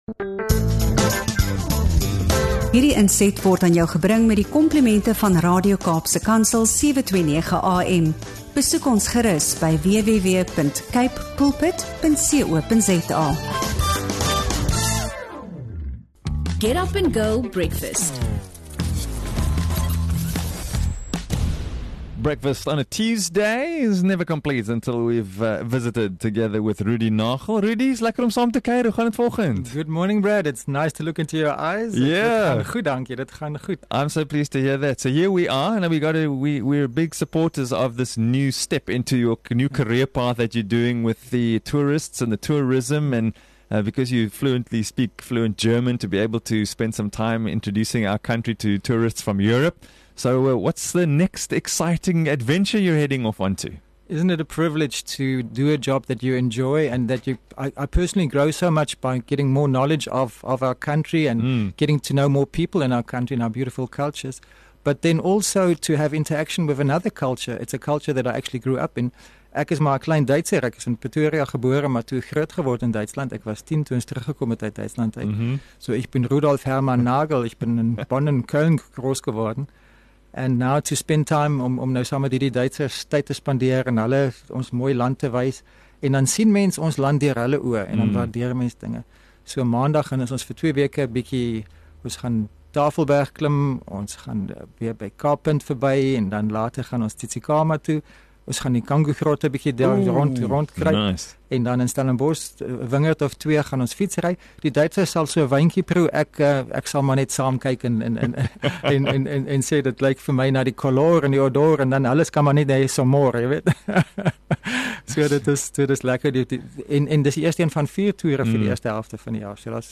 Ontbyt op ’n Dinsdag is nooit volledig sonder Get Up and Go Ontbyt en ’n betekenisvolle gesprek nie.